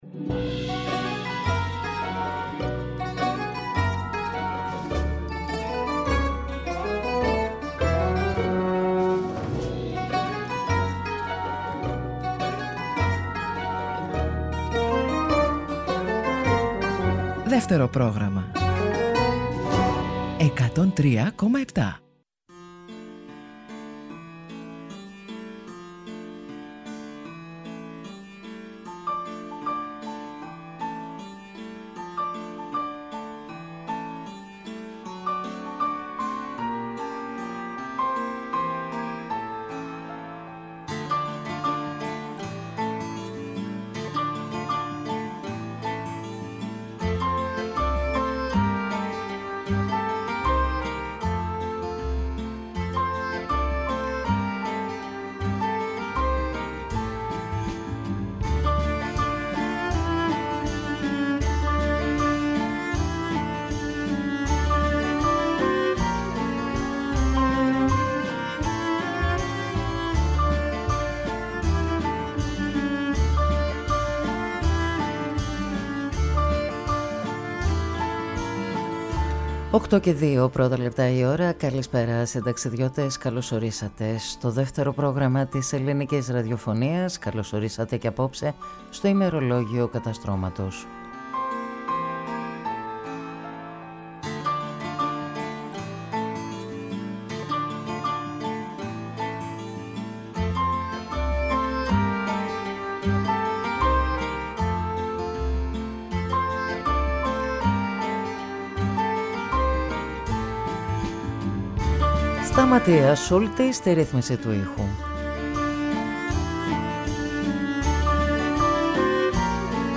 Συνταξιδιώτες οι ακροατές, ούριος άνεμος η μουσική και τα τραγούδια.